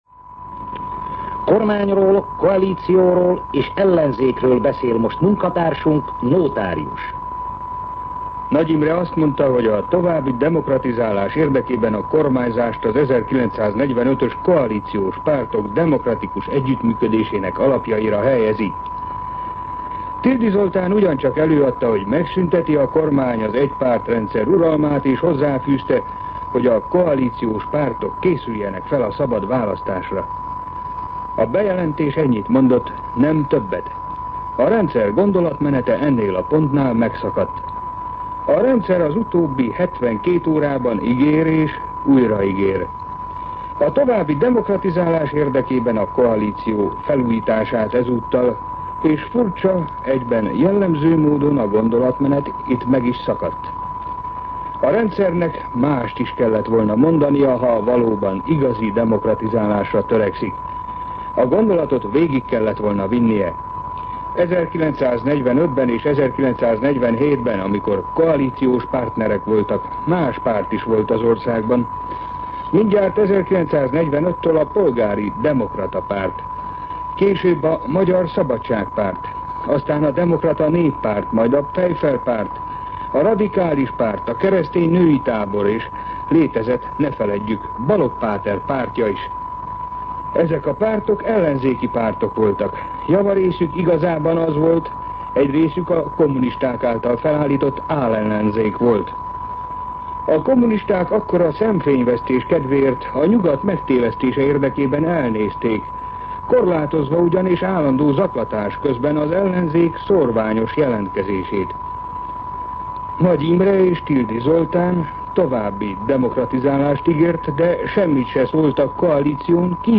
MűsorkategóriaKommentár